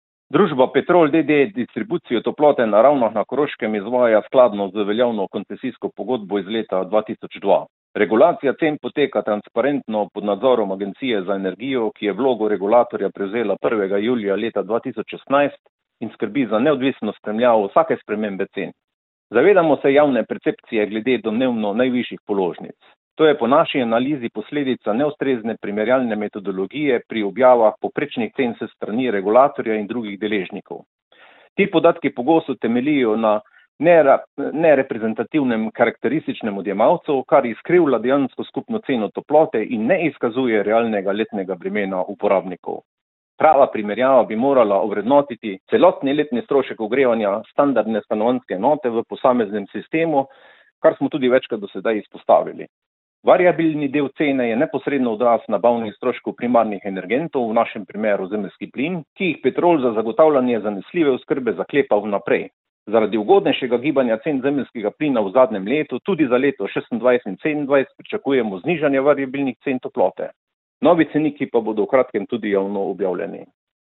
izjava Petrol _3.mp3